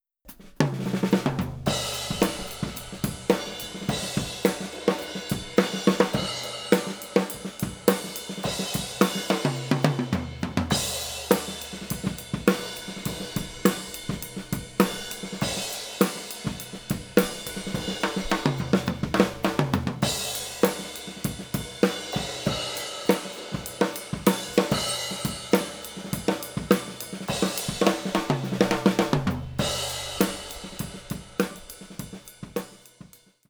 ドラム
それでは記事の順番通り、まずはドラムのトップにSR20を2本をセットしてレコーディングしたものです。
drum_top_2.wav